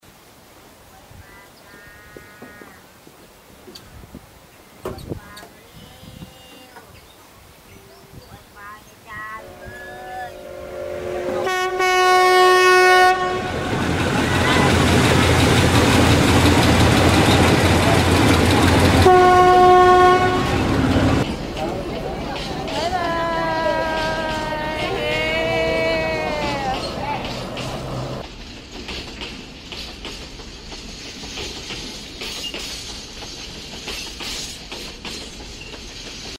เสียงบรรยากาศ
เสียงบรรยากาศสะพานข้ามแม่น้ำแคว.mp3